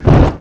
Звуки огнемёта
Звук зажженного огнемета